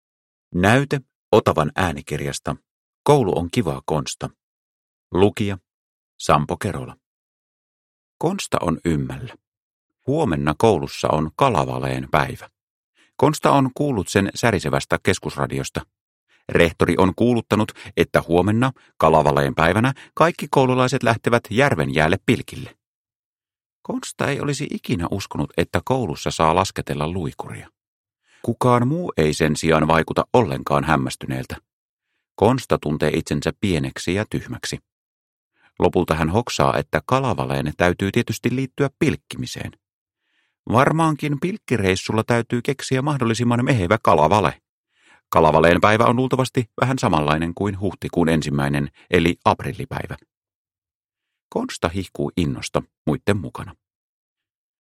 Koulu on kivaa, Konsta – Ljudbok – Laddas ner